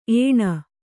♪ ēṇa